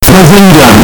Bazinga earrape
More Sounds in Earrape Soundboard
bazinga-earrape.mp3